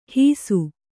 ♪ hīsu